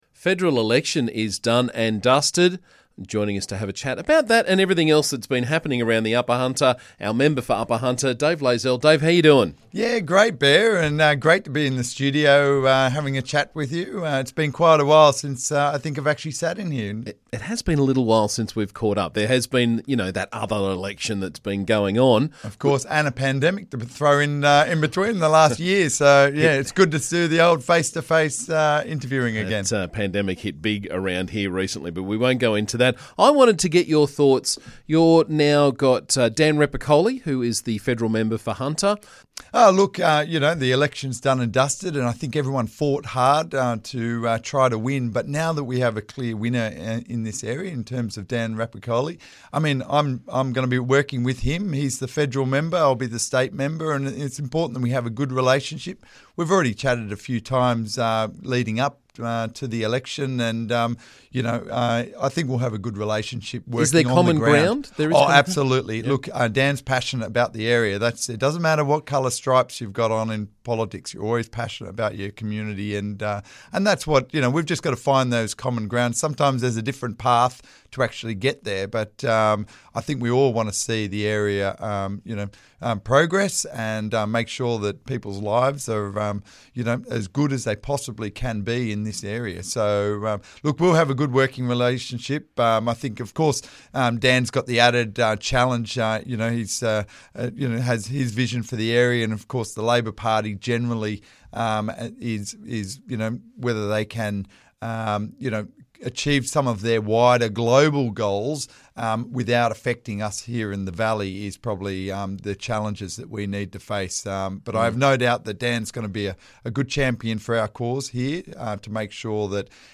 State Member for Upper Hunter, Dave Layzell joined us this morning to talk about the Federal election result and some other important issues from around the valley.